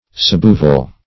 Suboval \Sub*o"val\, a. Somewhat oval; nearly oval.